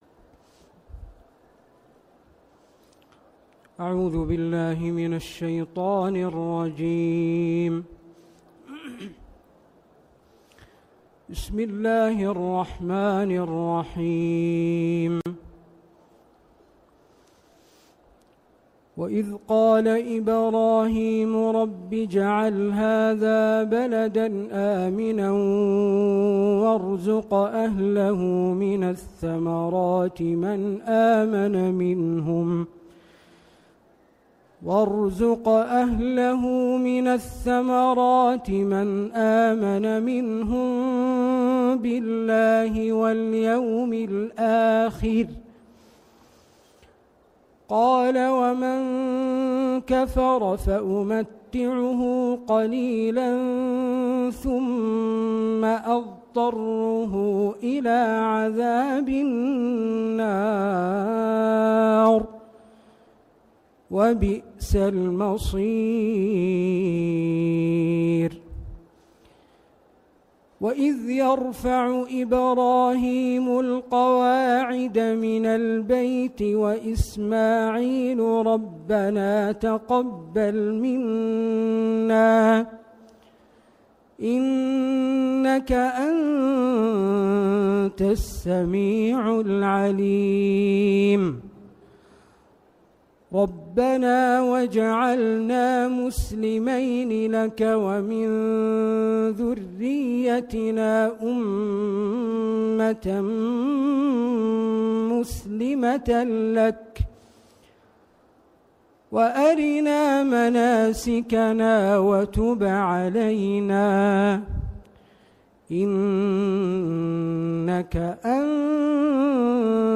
ندوة الفتوى في الحرمين الشريفين